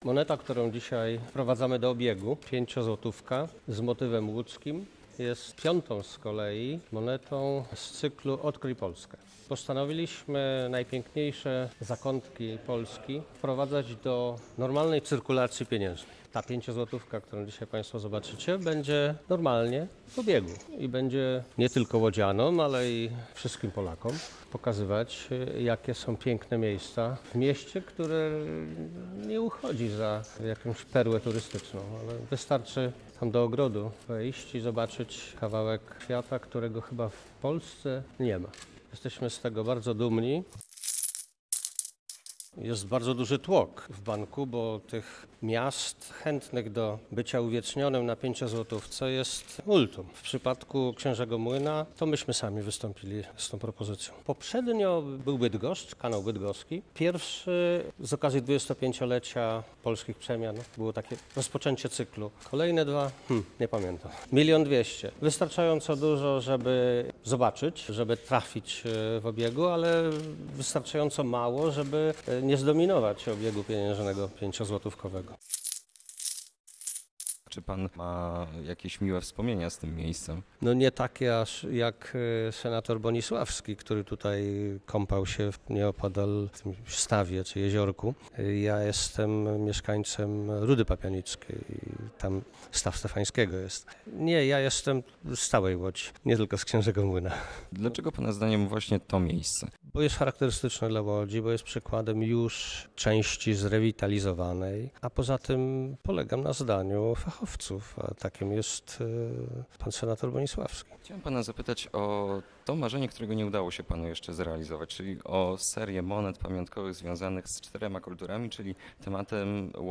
Posłuchaj rozmowy z Markiem Belką: